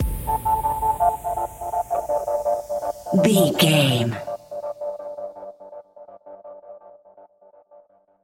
Fast paced
Aeolian/Minor
aggressive
dark
driving
energetic
piano
drum machine
synthesiser
breakbeat
synth bass